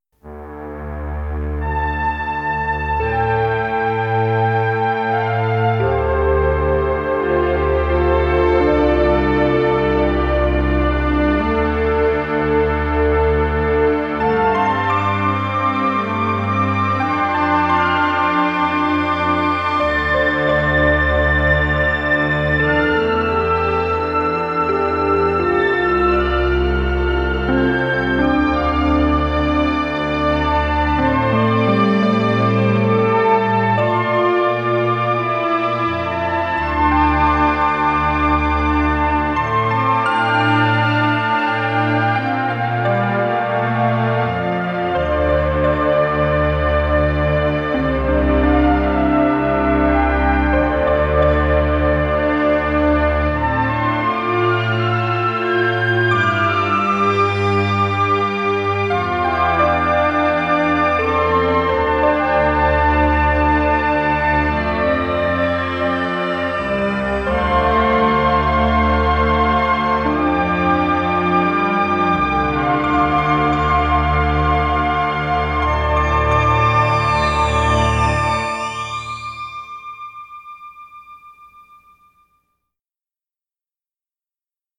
Some stuff I recorded years ago when I was experimenting with electronic music...